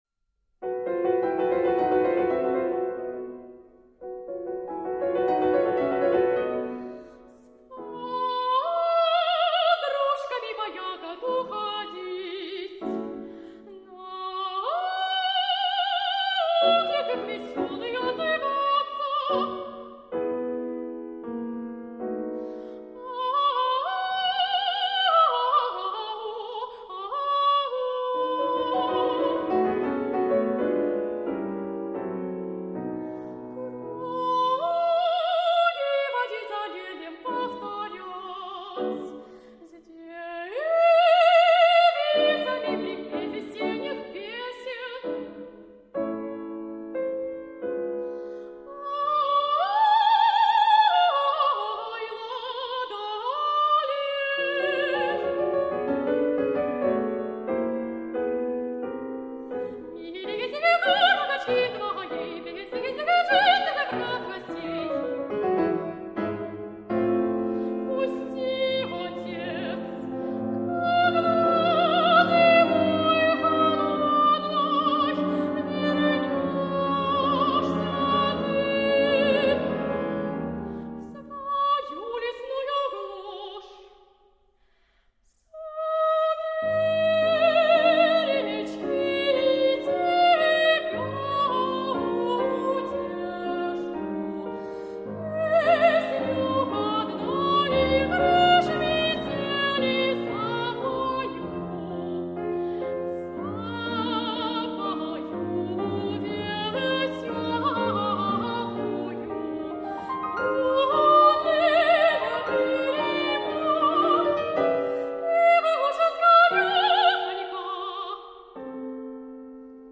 Maiden from the opera Snow Maiden by Rimsky-Korsakov(1).mp3
Оперная певица, опыт работы в Челябинском государственном театре оперы и балета, в оперетте!